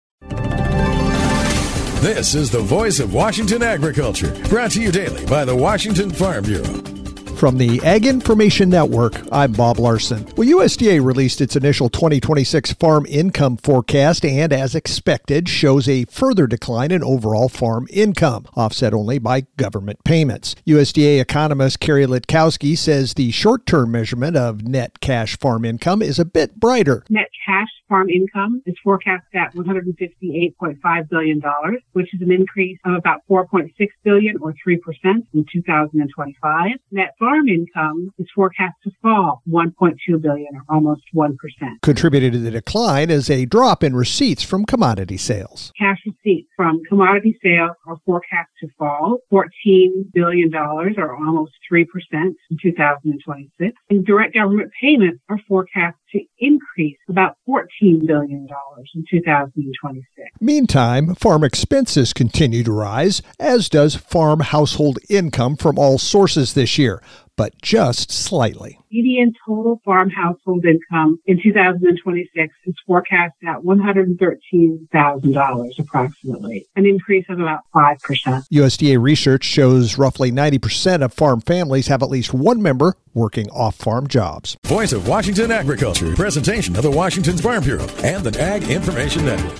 Thursday Feb 12th, 2026 89 Views Washington State Farm Bureau Report